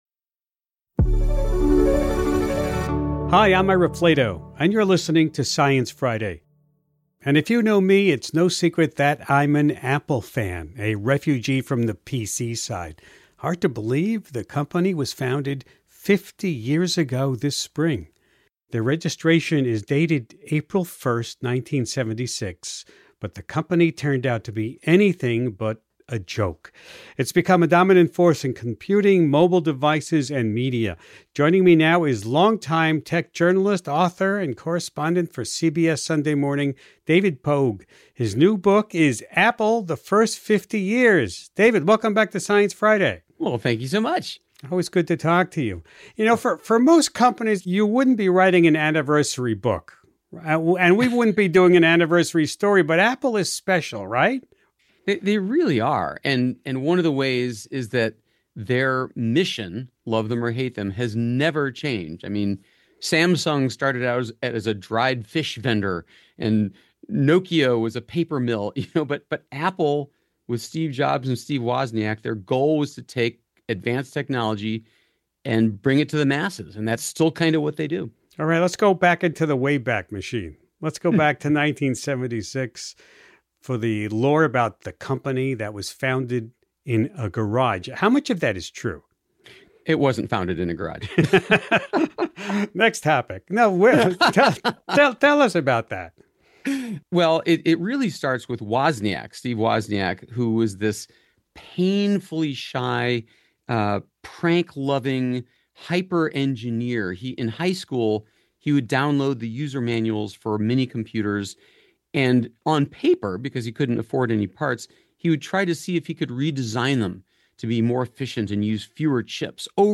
Tech journalist David Pogue talks with Ira Flatow about the backstory of the company, and the leadership of the mercurial Steve Jobs. He offers a peek into some lesser known chapters of the company’s history, like the ill-fated Apple Paladin, a prototype Apple-produced fax machine.